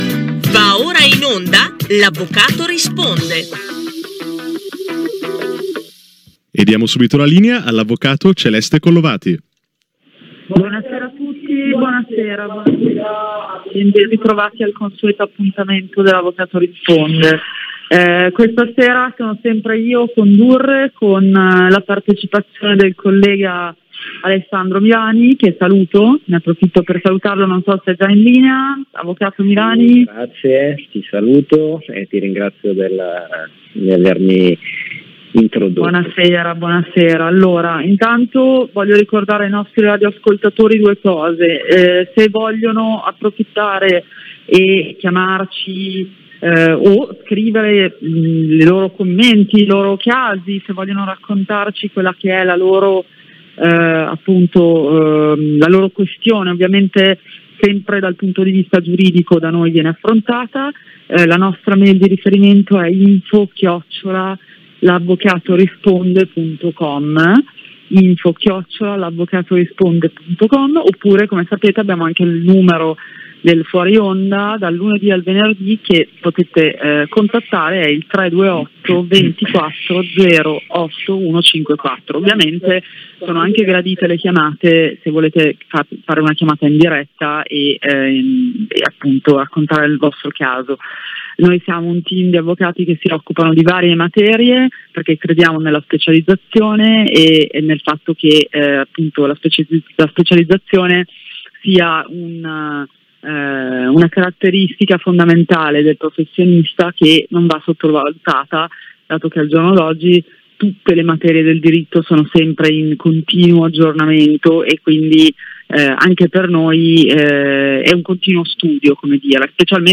trasmissione radio "L'avvocato risponde